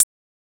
ClosedHH Groovin 4.wav